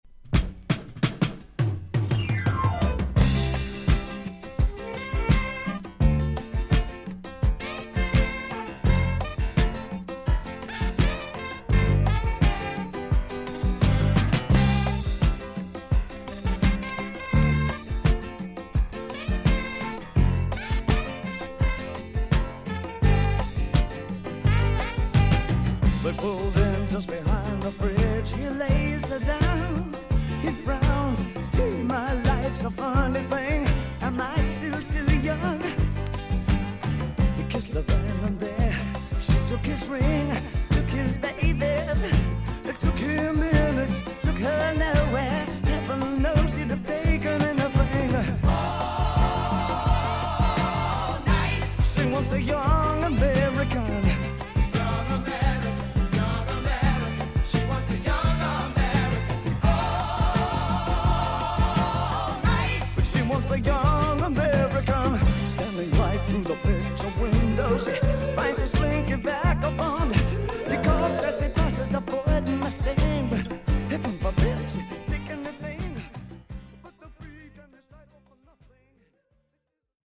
少々軽いパチノイズの箇所あり。クリアな音です。盤面に少々擦れ、浅いスジが見えますが音に出ません。